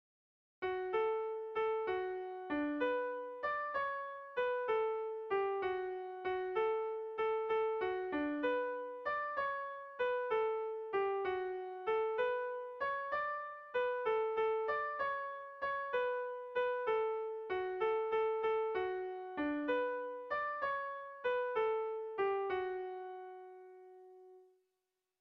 Kontakizunezkoa
Zortziko txikia (hg) / Lau puntuko txikia (ip)
AABA